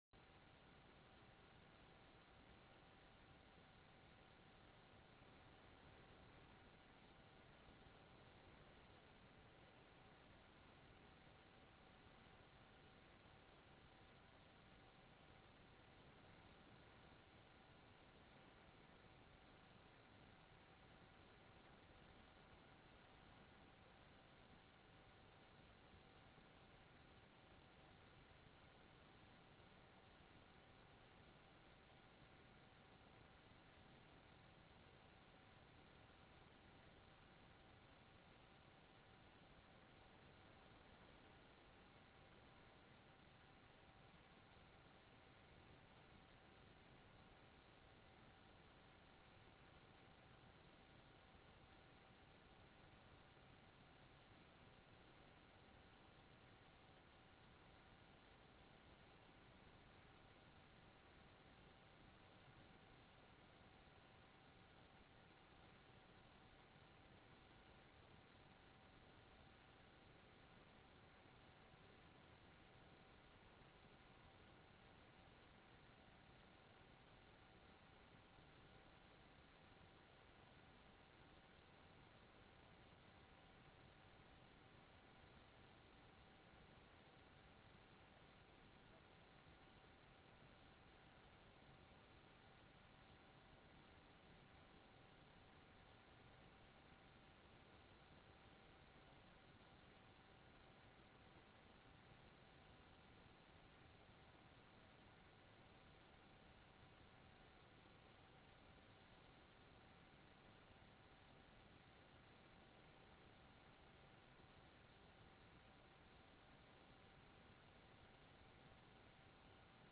Roughrider Room State Capitol Bismarck, ND United States